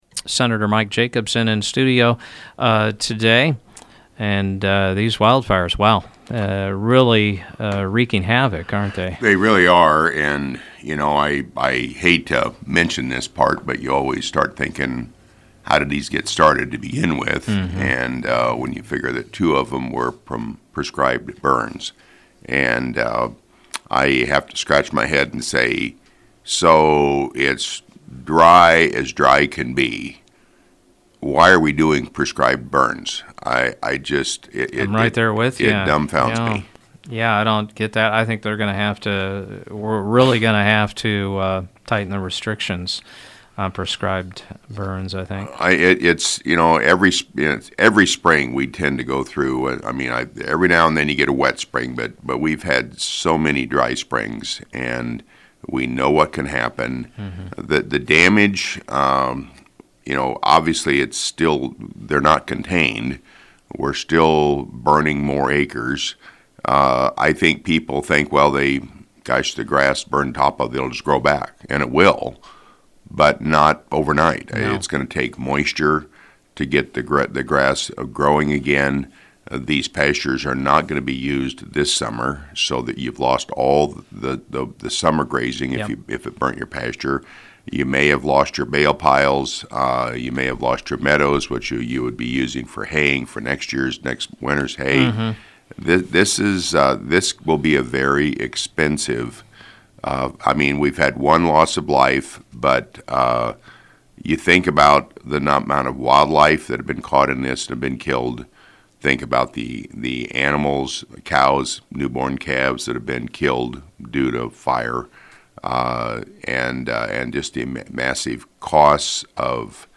District 42 State Senator Mike Jacobson was a guest on Mugs Monday and talked about the wildfires and budget bills the Legislature is dealing with: